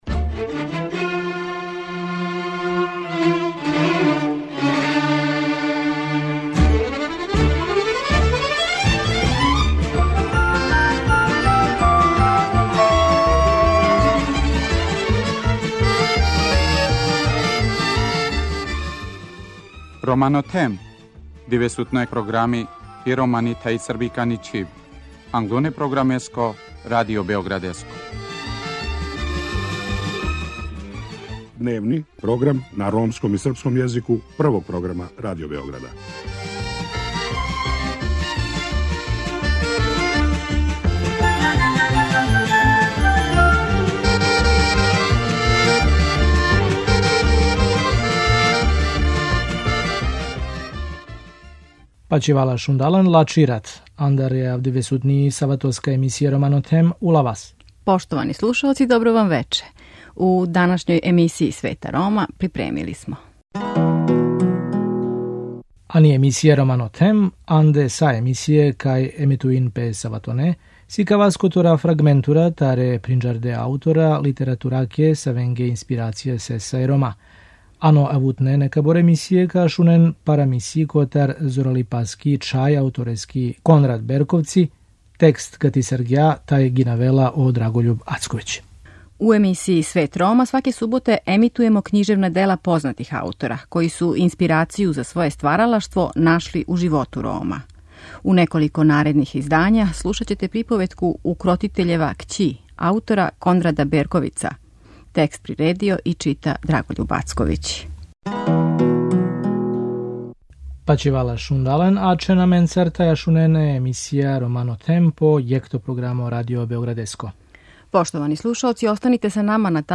У наредних неколико издања слушаћете приповетку Укротитељева кћи аутора Кондрада Берковица.